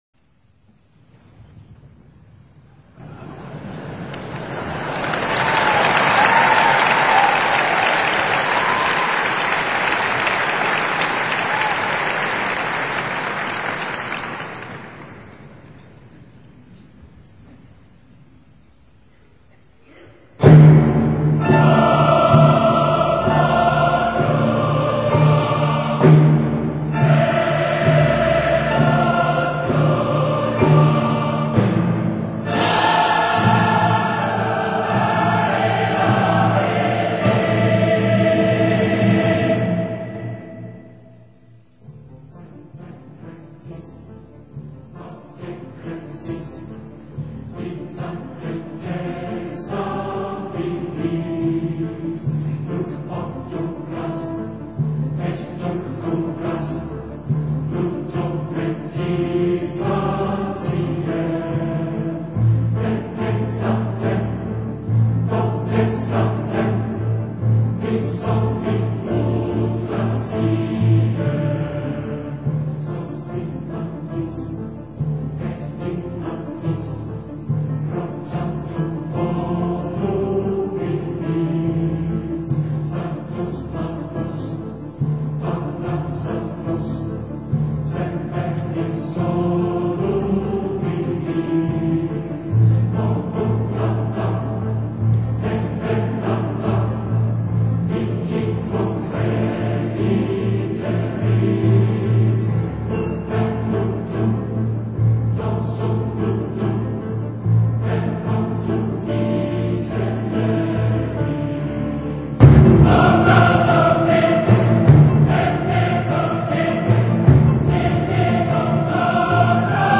Cheltenham Bach Choir, Choir of New College, Oxford, City of Birmingham Choir, City of Birmingham Symphony Youth Chorus, City of Glasgow Chorus, Glasgow Phoenix Choir, Harrogate Choral Society, Hertfordshire Choral Society, Ipswich Bach Choir, Joyful Company of Singers, Leicester Philharmonic Choir, Malvern Festival Chorus, Sheffield Philharmonic Chorus, Waltham Singers
soprano